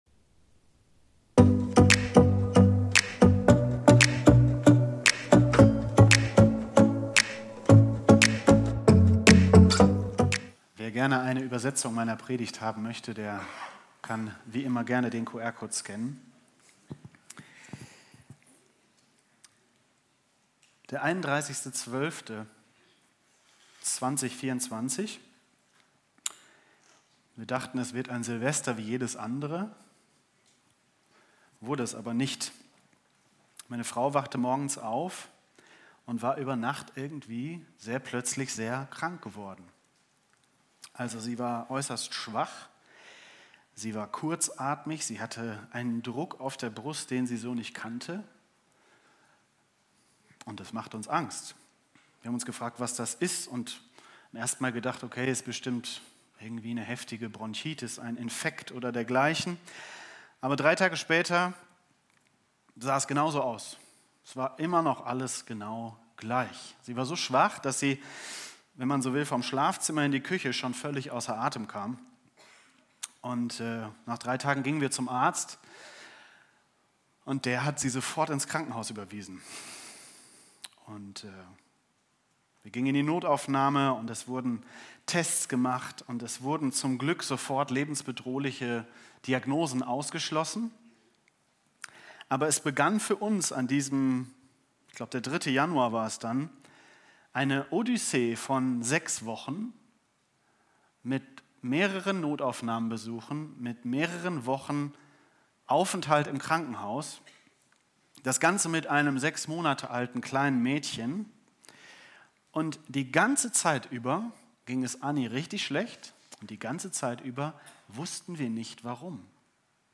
Live-Gottesdienst aus der Life Kirche Langenfeld.
Sonntaggottesdienst